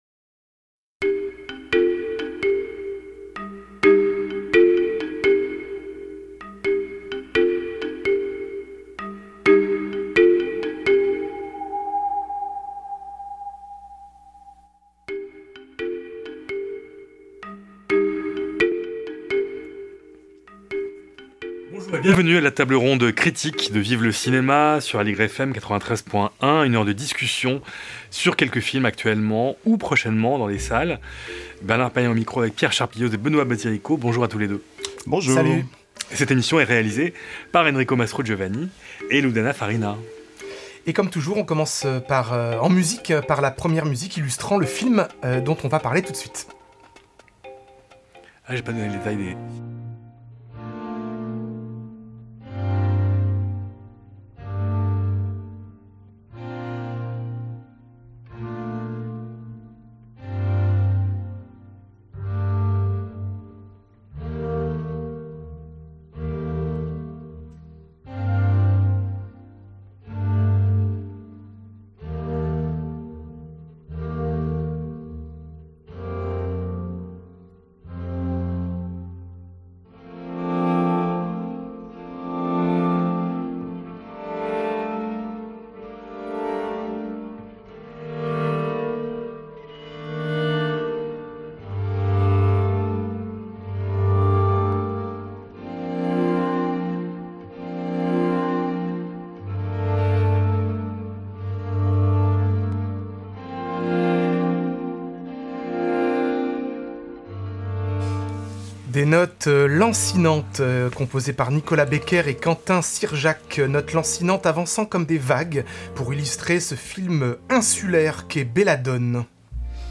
Table Ronde Critique de Vive Le Cinéma sur Aligre FM